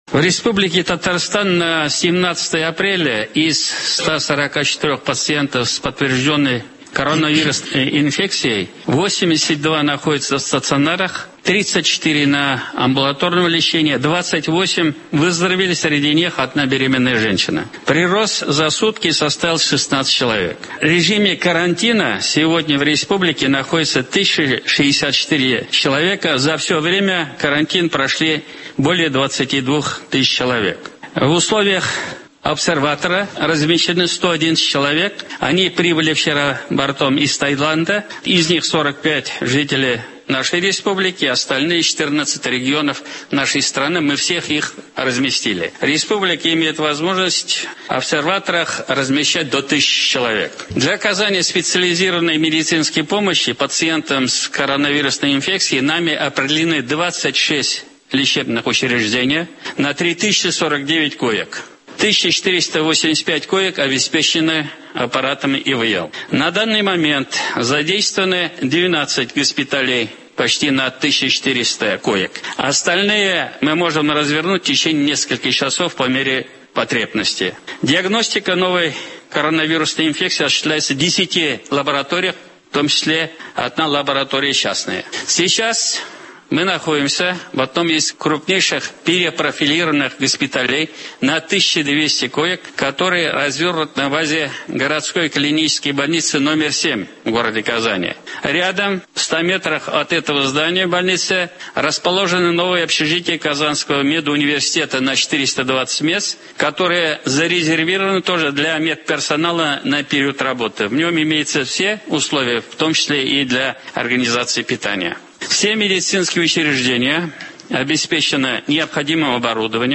Сегодня Президент Республики Татарстан Рустам Минниханов в режиме видеоконференцсвязи принял участие в совещании по вопросу готовности медицинских организаций к приему пациентов с новой коронавирусной инфекцией.
Рустам Минниханов доложил Владимиру Путину о ситуации в Татарстане: